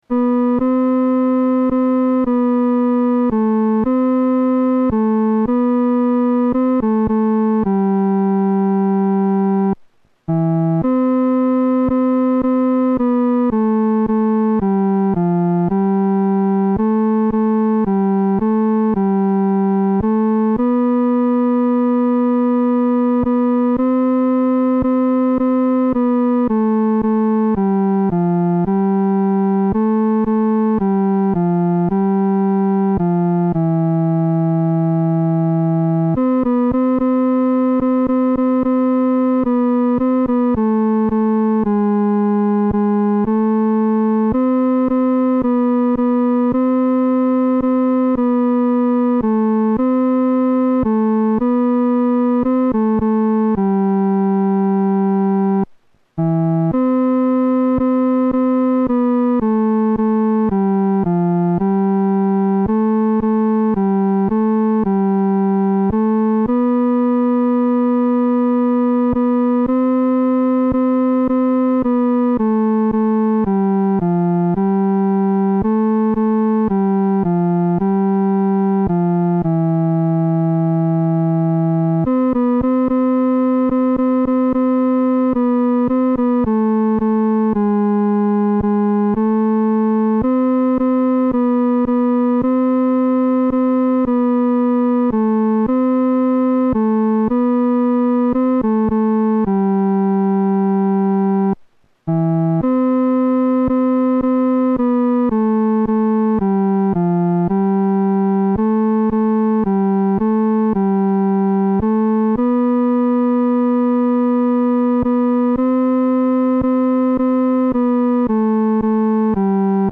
伴奏
男高
这是一首抒情的圣诗，我们当用感恩的心唱颂。